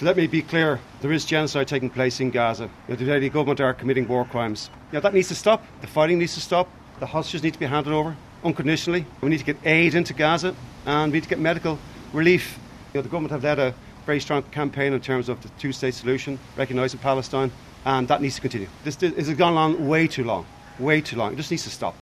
The former Dublin football manager was speaking at the Fianna Fáil think-in in Cork, after he was criticised by Sinn Féin’s Pearse Doherty last week.
Jim Gavin was asked if the phrase was appropriate: